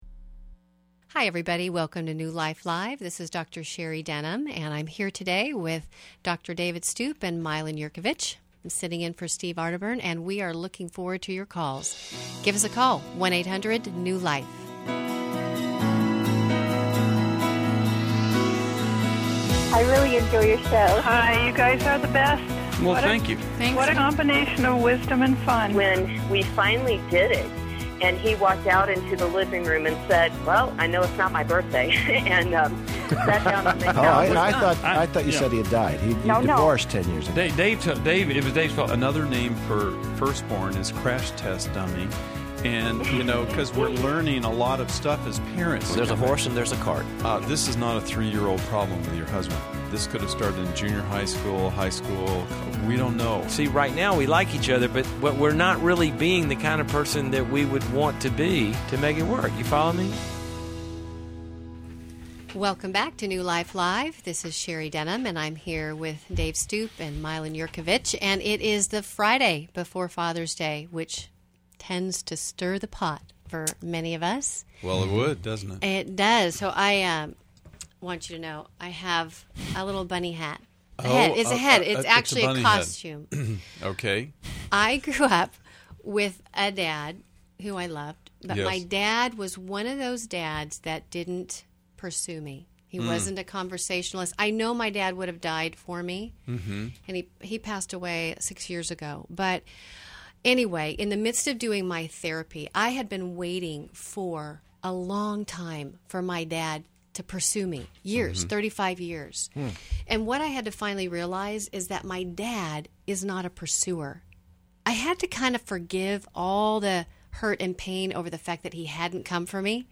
New Life Live: June 17, 2011 explores tough topics like substance abuse, parenting challenges, body image concerns, and dating dilemmas through caller questions.